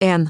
OCEFIAudio_ru_LetterN.wav